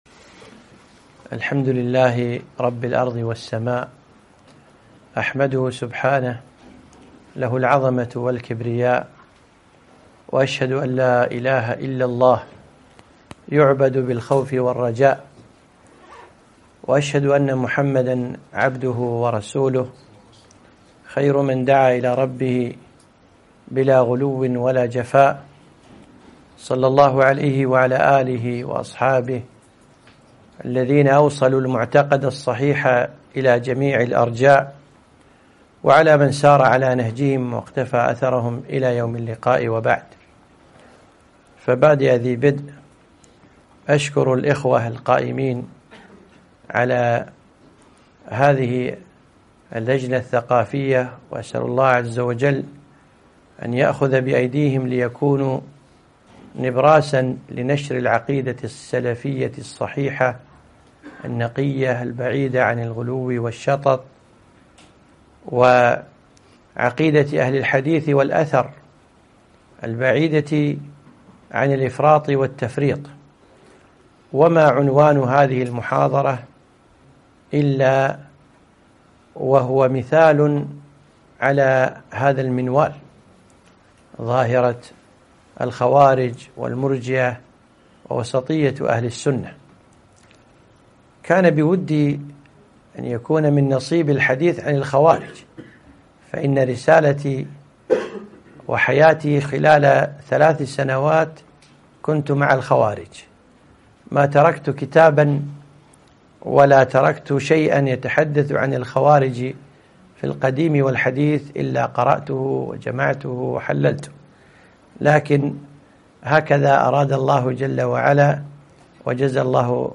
محاضرة - فكر الإرجاء قديما وحديثا